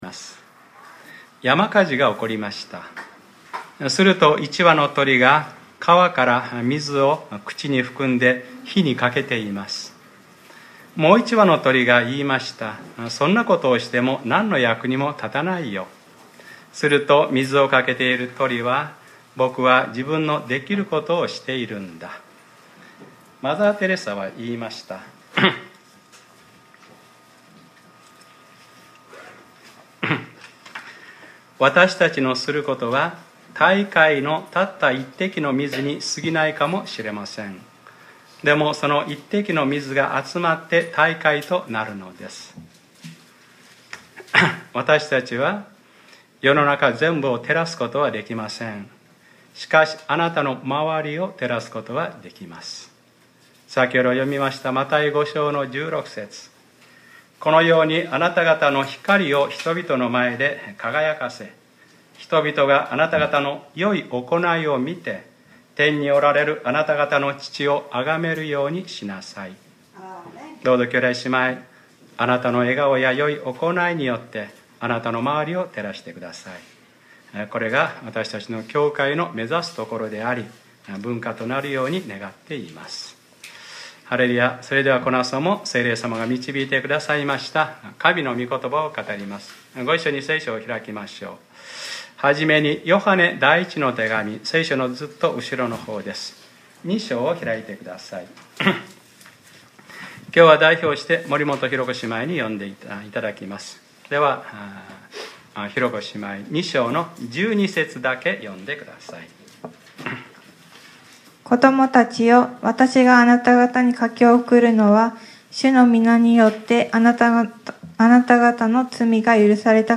2015年05月31日（日）礼拝説教 『Ⅰヨハネｰ４：世と世の欲は滅び去ります』